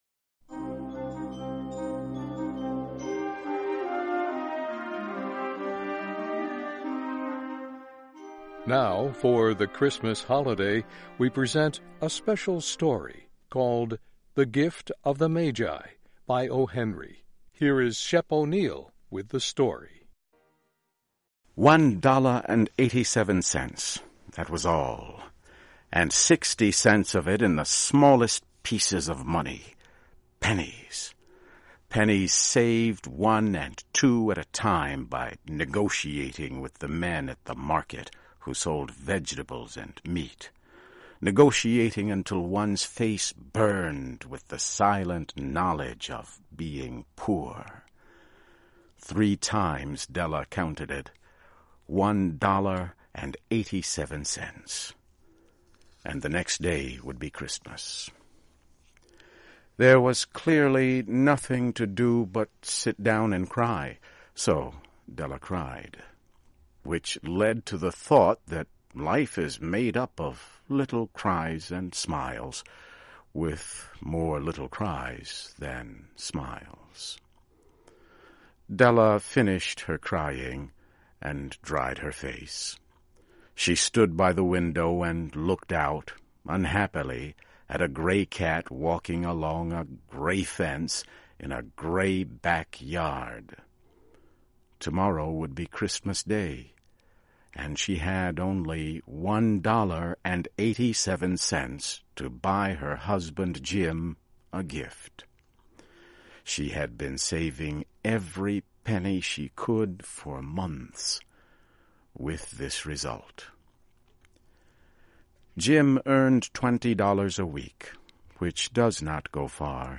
Learn English as you read and listen to a weekly show with short stories by famous American authors. Adaptations are written at the intermediate and upper-beginner level and are read one-third slower than regular VOA English.